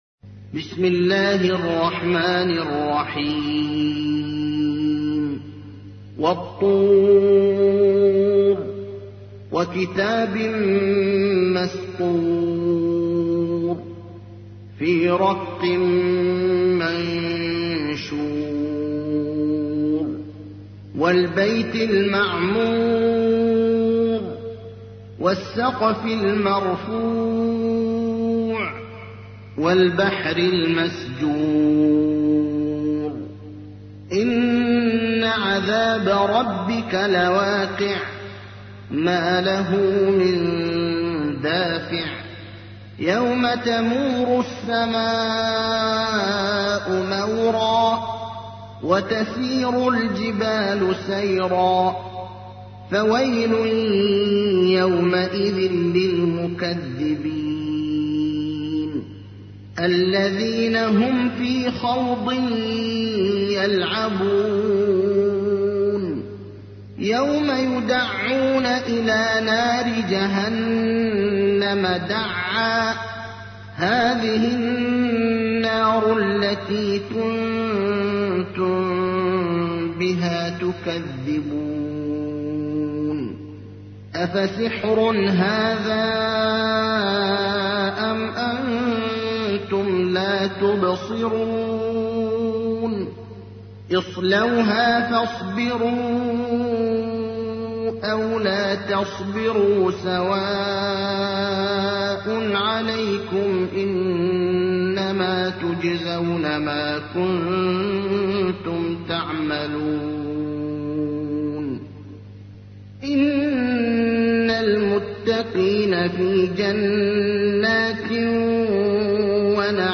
تحميل : 52. سورة الطور / القارئ ابراهيم الأخضر / القرآن الكريم / موقع يا حسين